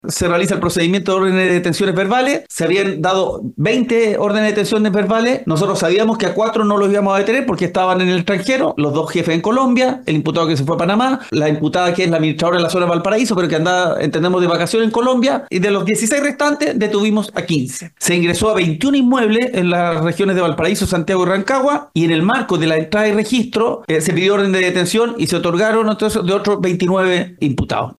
cu-audiencia-de-42-imputados-en-vina-fiscalia-.mp3